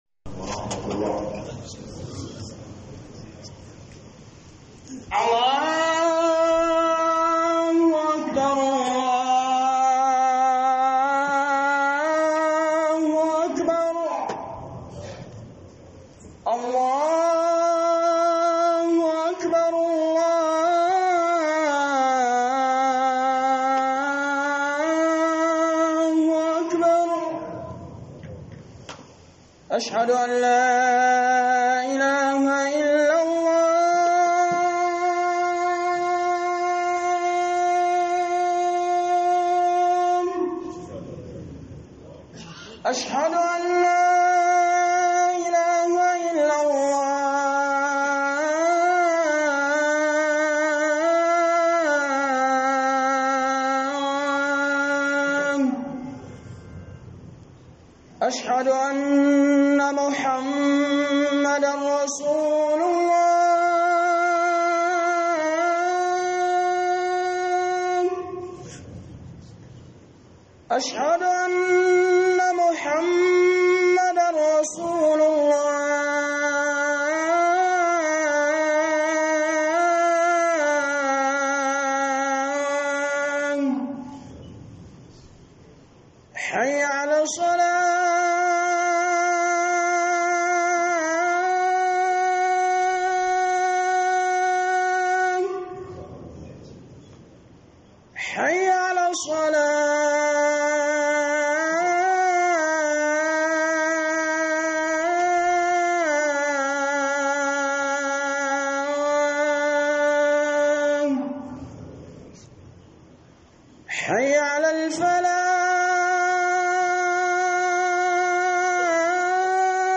AMANA - Huduba